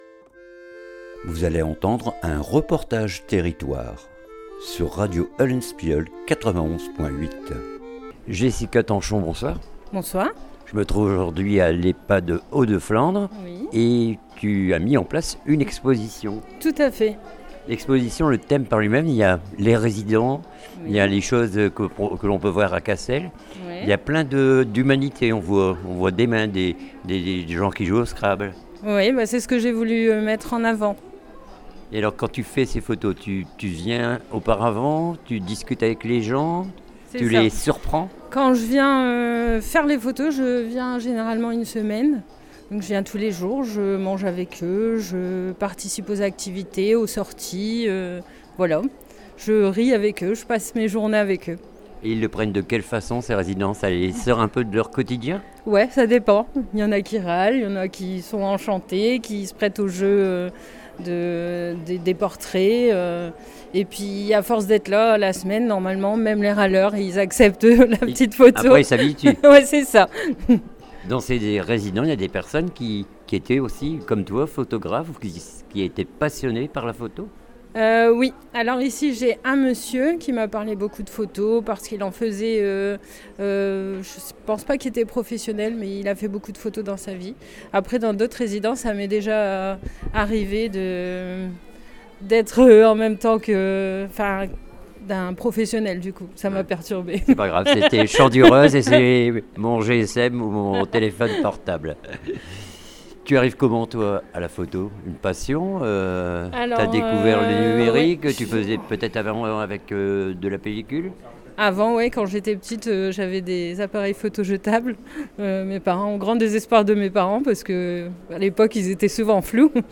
REPORTAGE TERRITOIRE EXPO PHOTO MAGIE DU MOMENT CASSEL